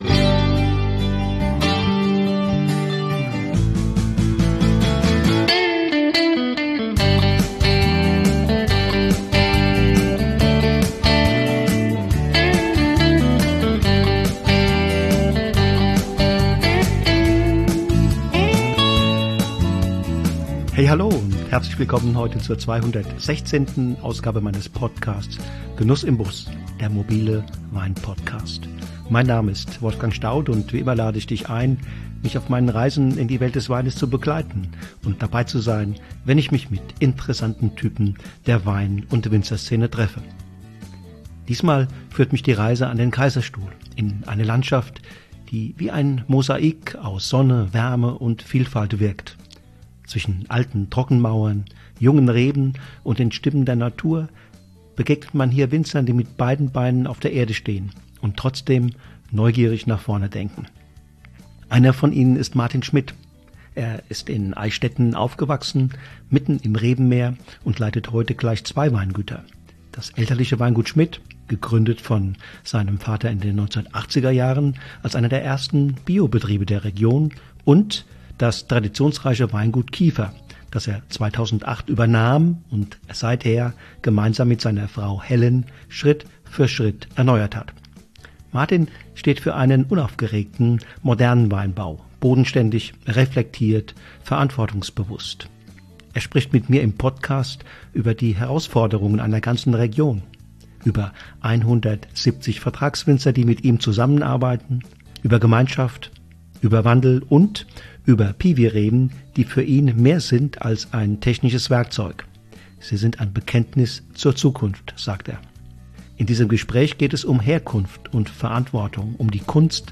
Ein ruhiges, tiefes Gespräch über Herkunft, Aufbruch und die Kunst, Wein neu zu denken.